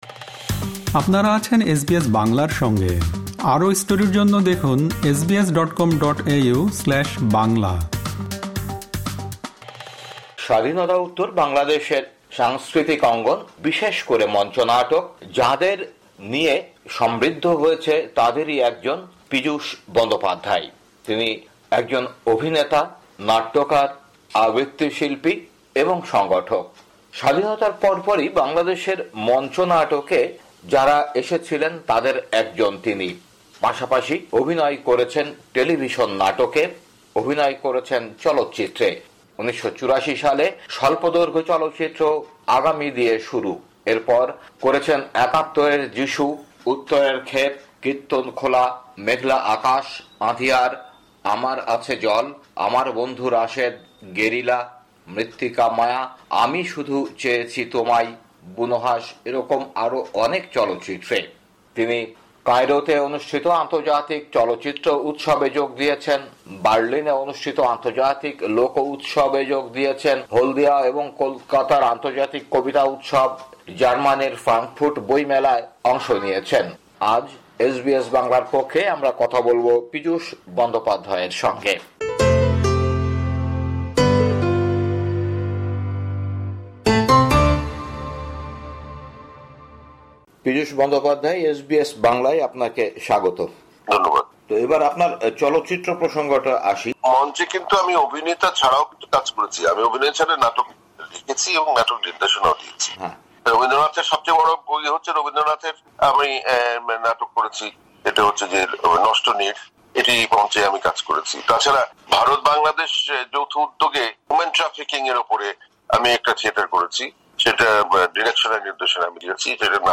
বাংলাদেশের প্রখ্যাত অভিনেতা ও সাংস্কৃতিক কর্মী পীযূষ বন্দ্যোপাধ্যায় কথা বলেছেন এসবিএস বাংলার সঙ্গে।